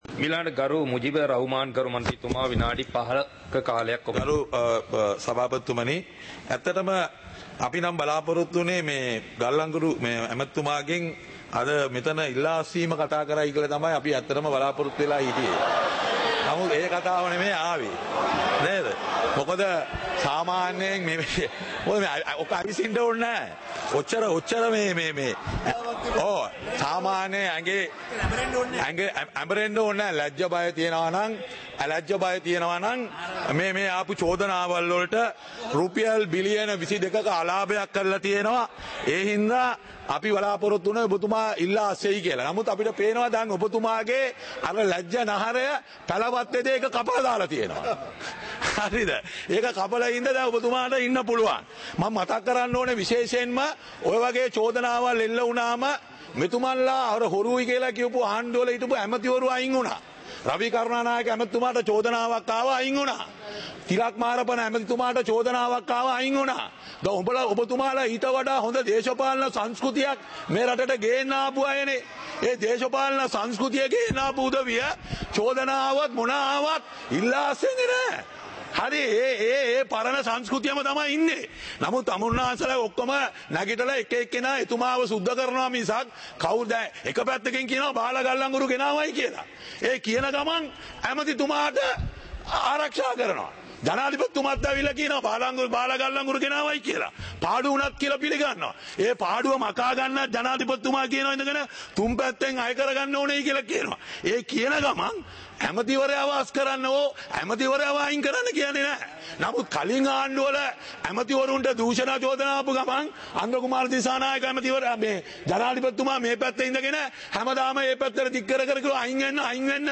Parliament Live - Recorded